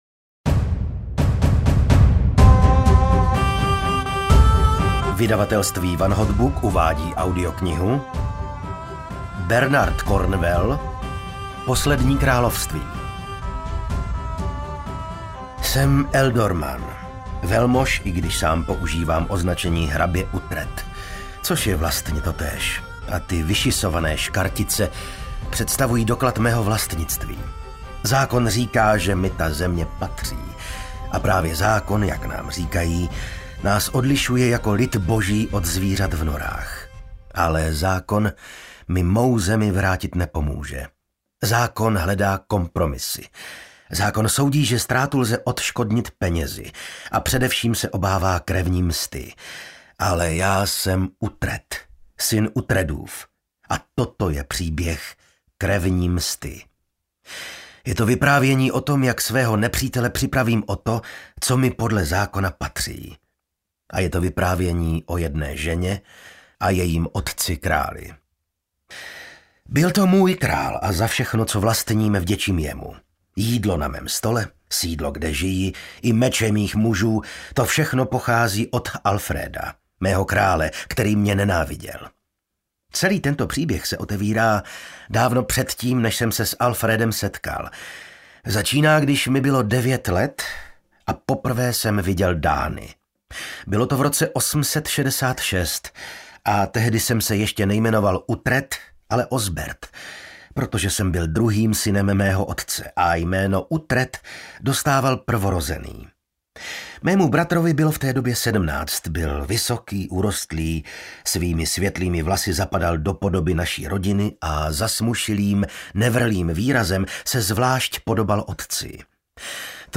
Poslední království audiokniha
Ukázka z knihy